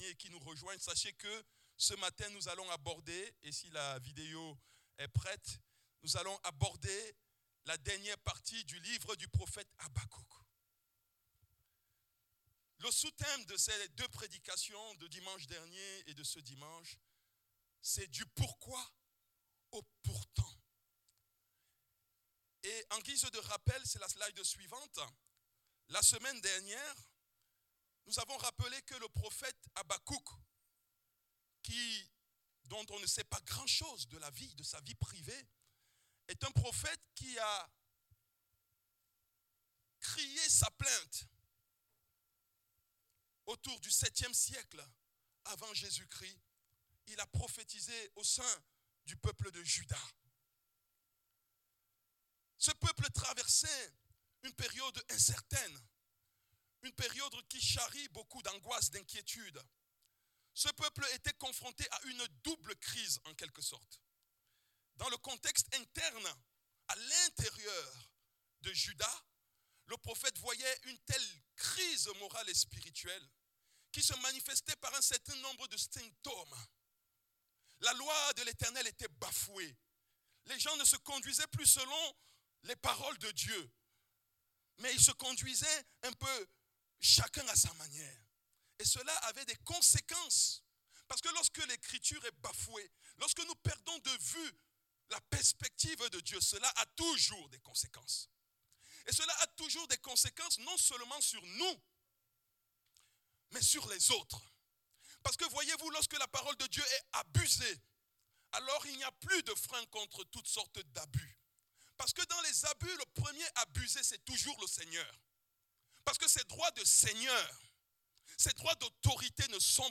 Culte du dimanche 31 août 2025